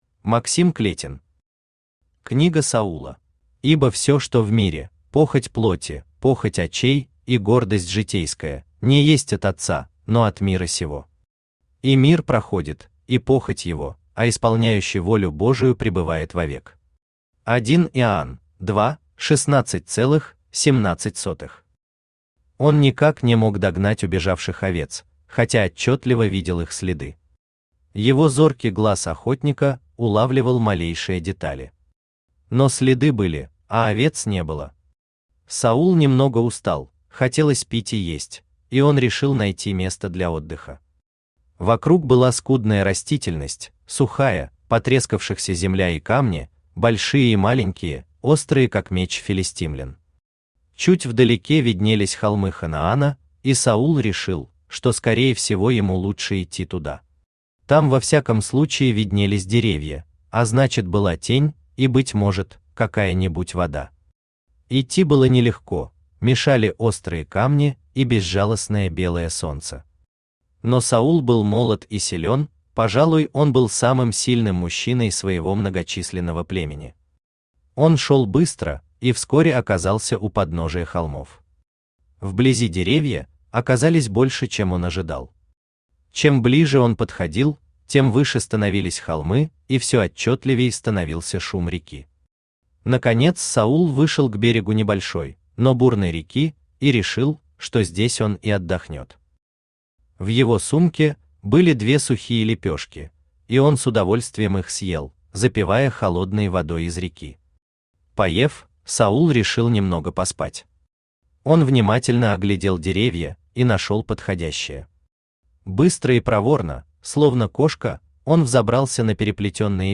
Aудиокнига Книга Саула Автор Максим Клетин Читает аудиокнигу Авточтец ЛитРес.